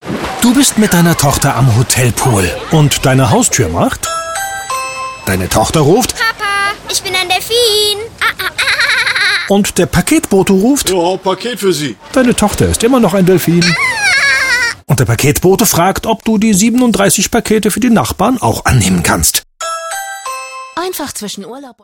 markant, sehr variabel
Mittel plus (35-65)
Commercial (Werbung)